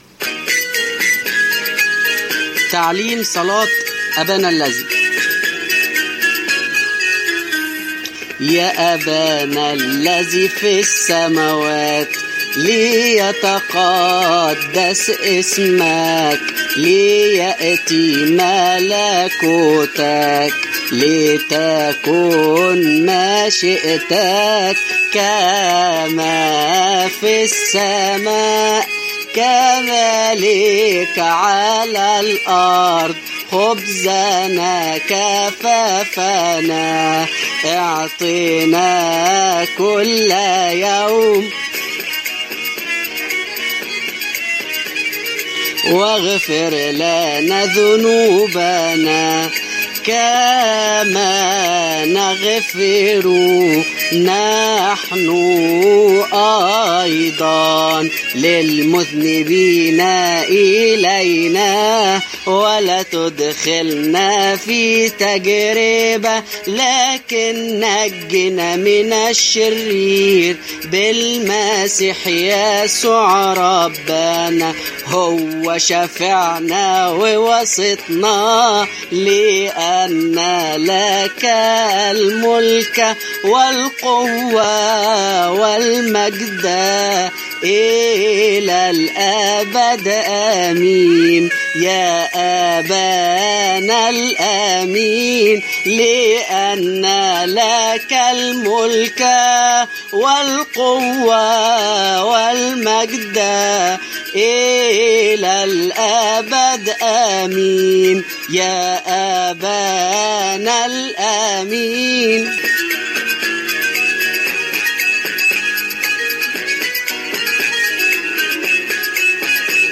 مزامير وصلوات مرنمة بشكل سهل الحفظ للأطفال